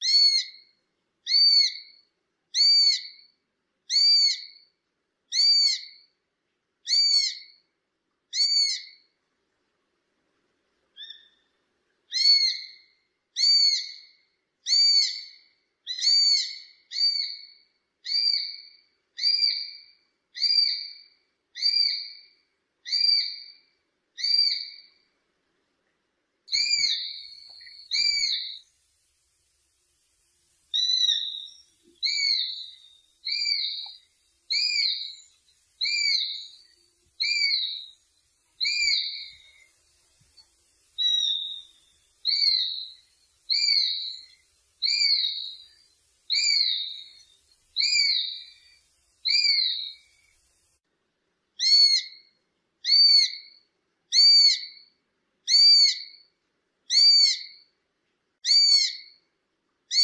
Tiếng Diều Hâu săn mồi mp3